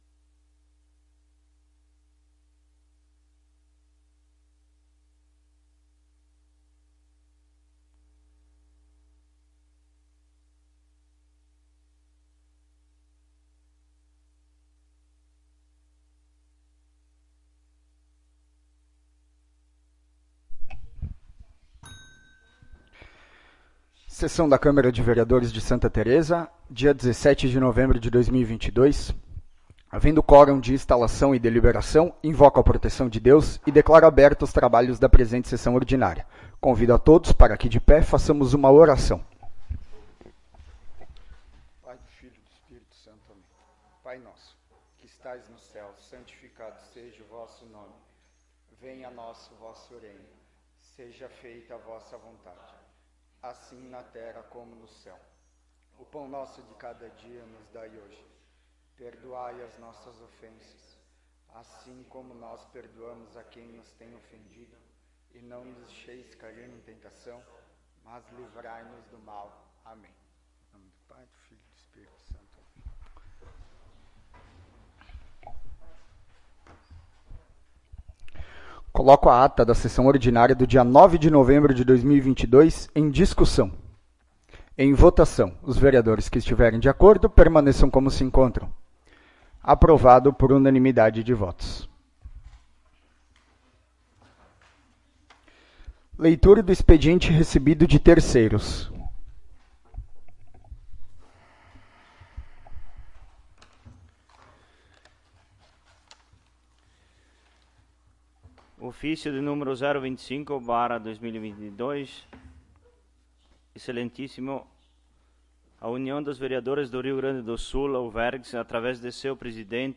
20° Sessão Ordinária de 2022
Áudio da Sessão